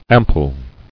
[am·pule]